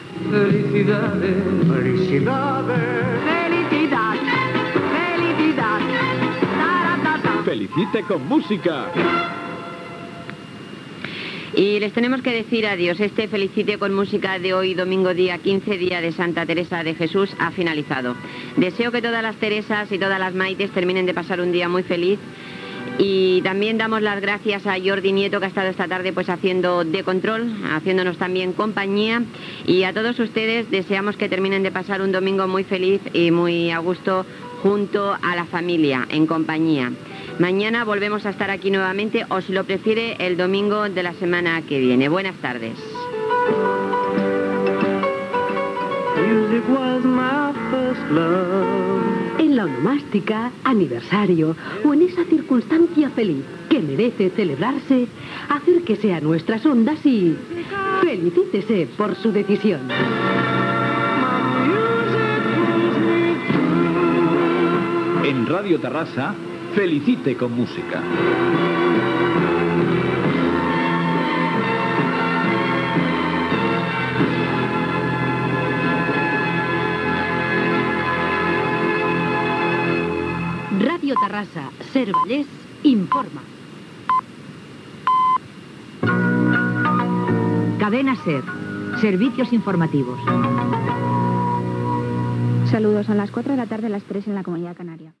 Comiat del programa, careta del programa, connexió amb les notícies de la Cadena SER
Musical